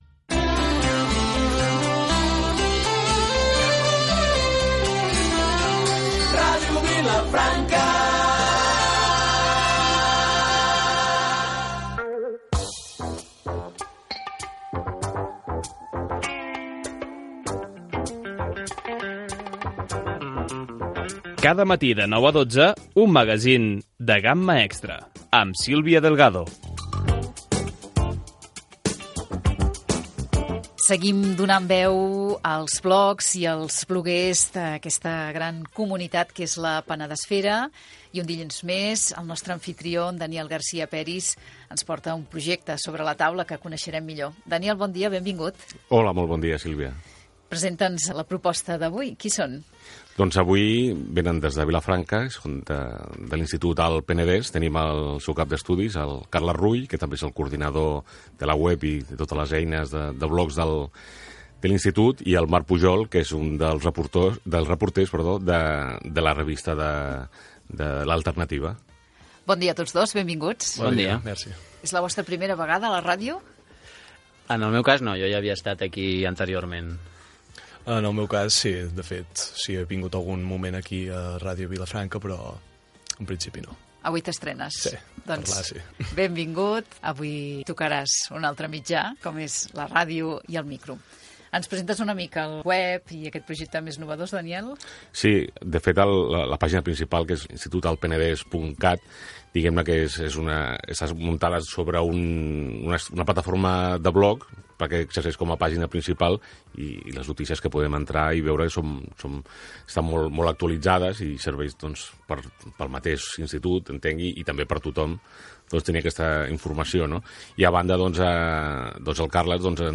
0362f1cffd89bc91323b37da955218f09b6f3da9.mp3 Títol Ràdio Vilafranca Emissora Ràdio Vilafranca Titularitat Pública municipal Nom programa Un magazín de gamma extra Descripció Indicatius de la ràdio i del programa, secció "La Penedesfera". Presentació dels invitats i com va sorgir la revista ALTernativa de l'Institut Alt Penedès